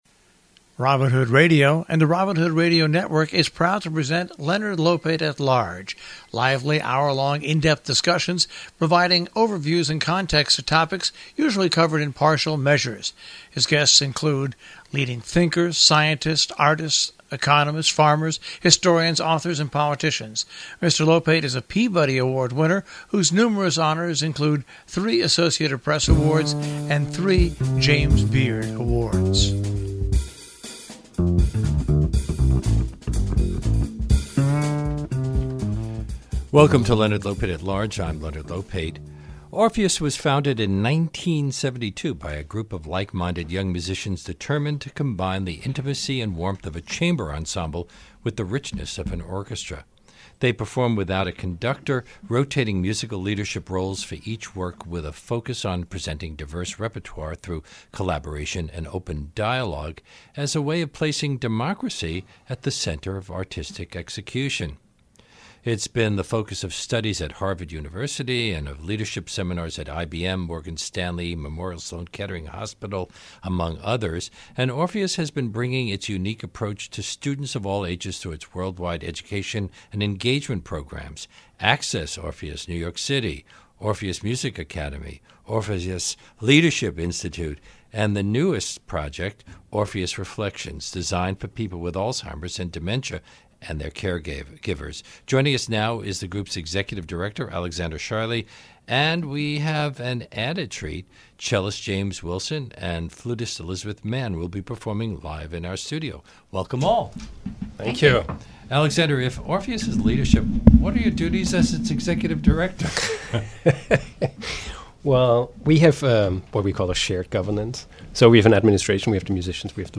Today, on Leonard Lopate at Large, Leonard talks with the directors of Orpheus Chamber Orchestra about their unique approach to performing classical music.